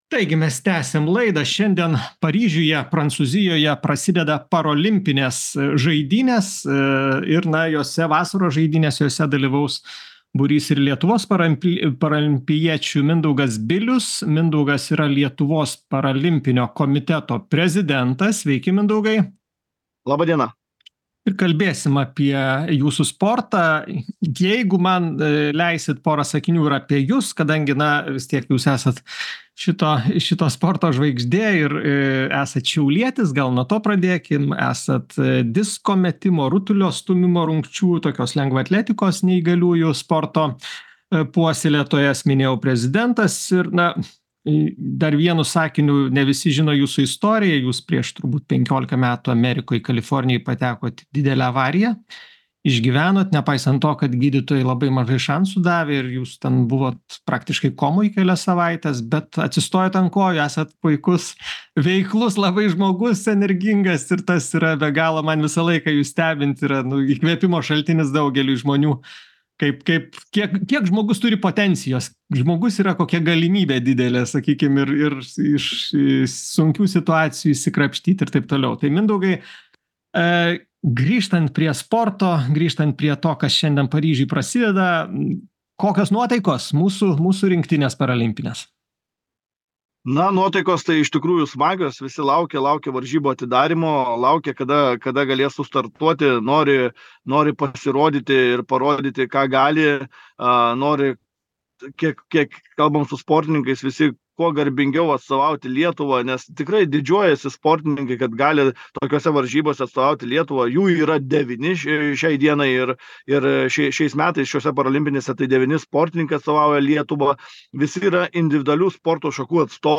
Šiandien Paryžiuje prasideda vasaros paralimpinės žaidynės. Jose dalyvaus 9 Lietuvos sportininkai. Laidoje – pokalbis